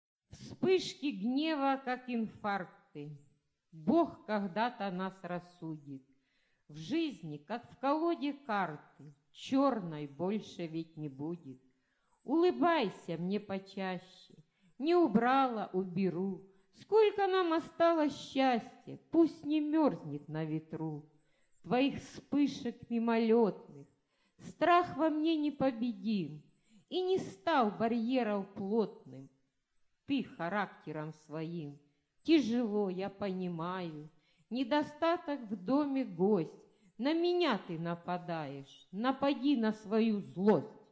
Стих Надиктован автором